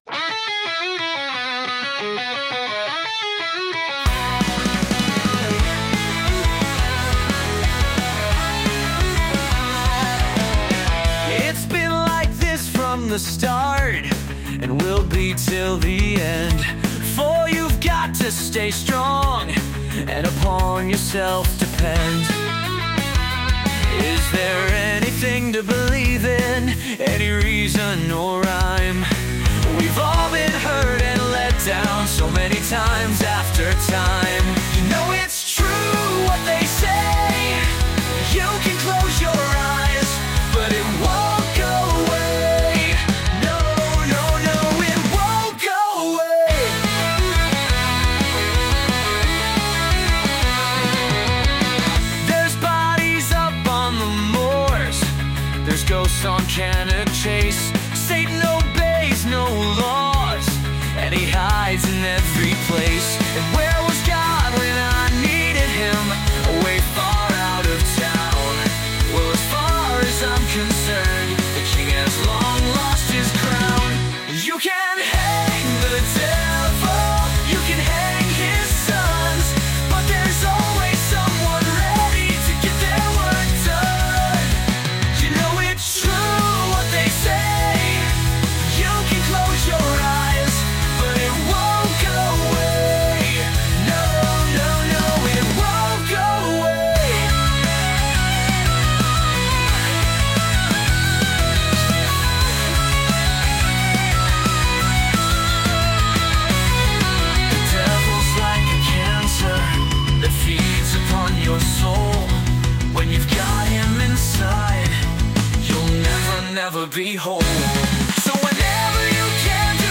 dark and introspective song